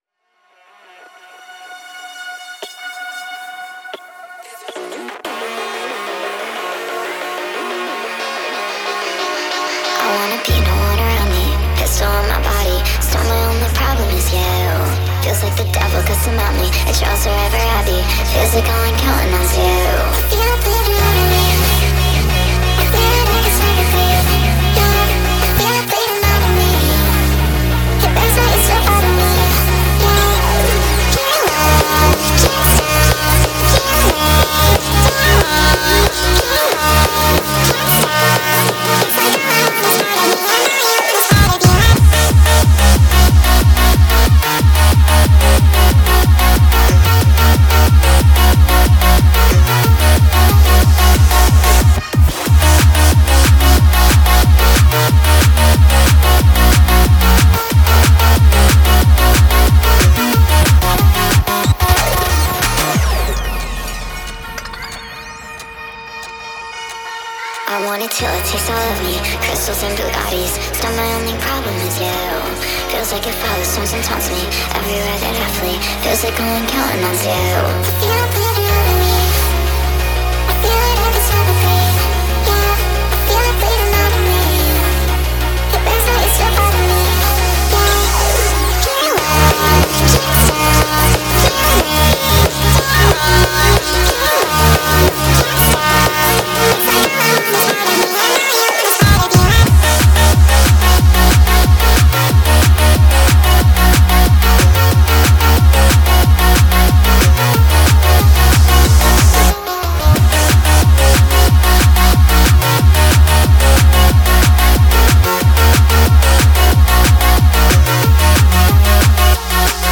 My nightcore edit: